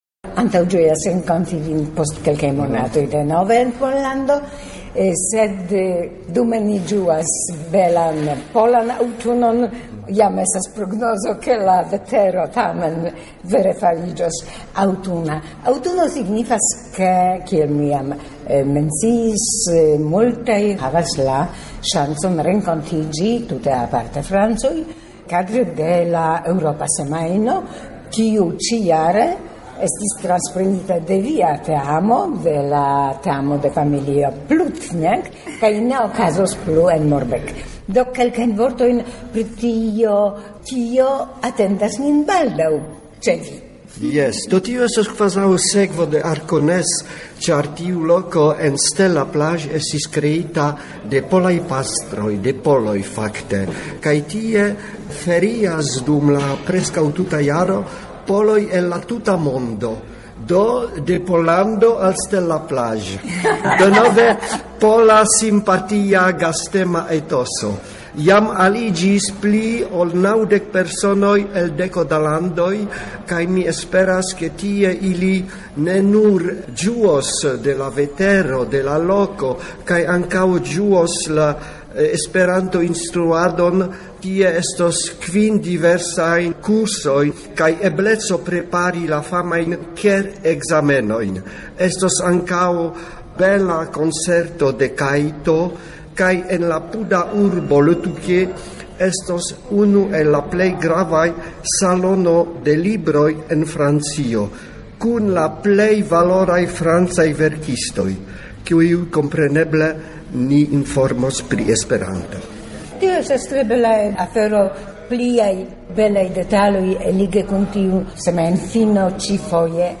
Interview
Pour faciliter l'écoute, la bande son a été légèrement ralentie.